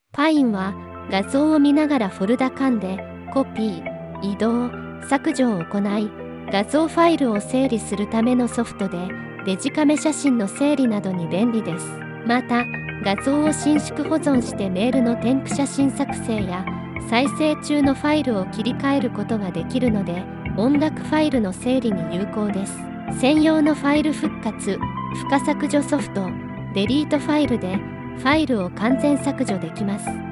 Voice紹介
VOICEVOX Nemo pine0.90.mp3